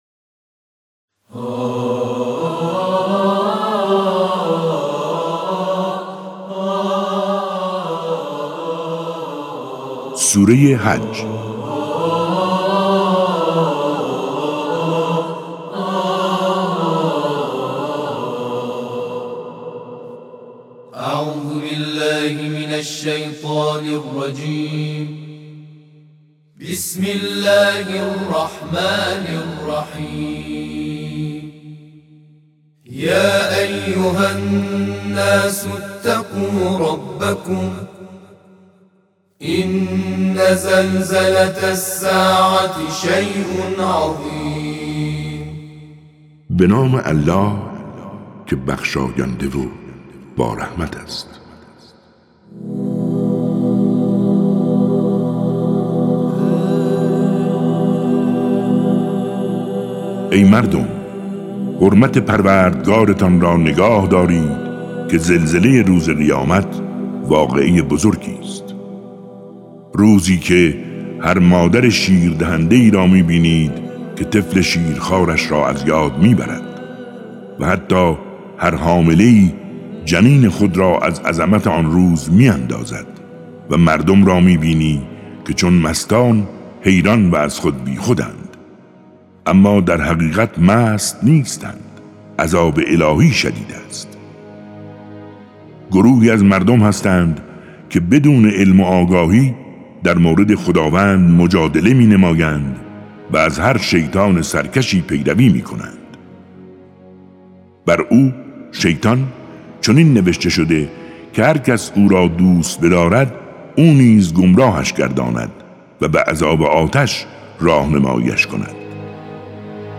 کتاب صوتی سوره حج (22)به صورت همخوانی به همراه ترجمه مسعود ریاعی با صدای بهروز رضوی
کتاب صوتی